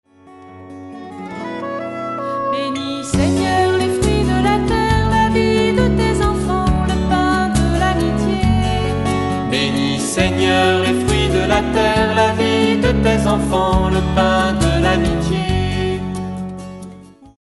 Ces extraits vous sont proposés en toute humilité, leur auteur ne prétentant pas bien chanter (!). Ils vous sont proposés pour leur tempo et pour vous donner une petite idée de la mélodie, à défaut d'une parfaite harmonie (mes excuses auprès des mélomanes !).